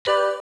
Index of /phonetones/unzipped/LG/A200/Keytone sounds/Sound2